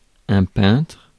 Ääntäminen
Synonyymit (halventava) barbouilleur Ääntäminen France: IPA: /pɛ̃tʁ/ Haettu sana löytyi näillä lähdekielillä: ranska Käännös Ääninäyte Substantiivit 1. painter UK US Suku: m .